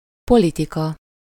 Ääntäminen
Ääntäminen Tuntematon aksentti: IPA: /pʊlɪtiːk/ Lyhenteet (leksikografia) polit.